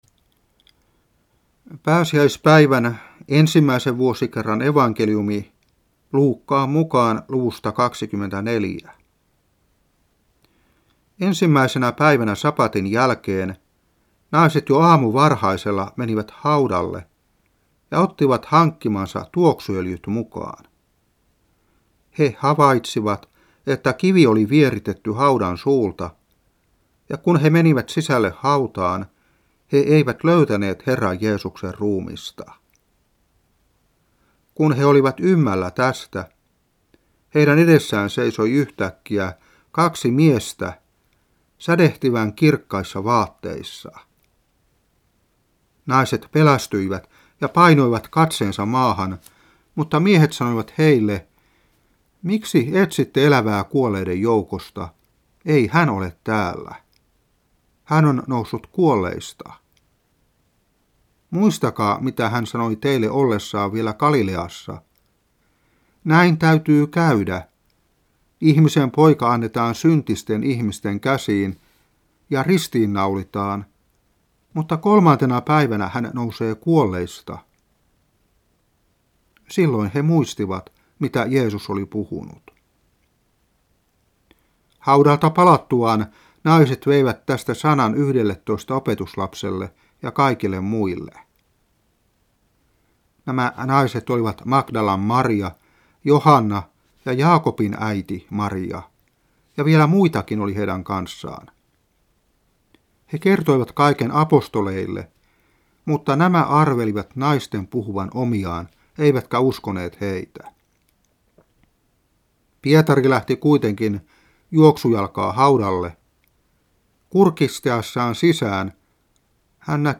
Saarna 1992-4.